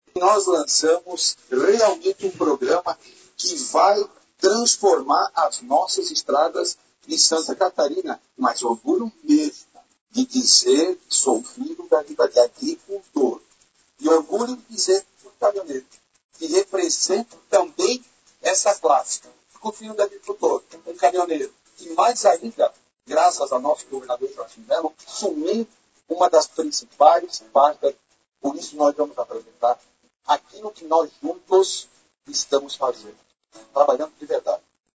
O secretário da Infraestrutura e Mobilidade (SIE), Jerry Comper, foi um dos palestrantes do painel dedicado ao setor em Santa Catarina no último dia do Congresso de Municípios, Associações e Consórcios de SC – Comac 2024, promovido pela Federação Catarinense dos Municípios.
A fala do titular da Infraestrutura estadual foi dirigida a prefeitos (eleitos e os que estão em fim de mandato), além de vereadores e outras lideranças: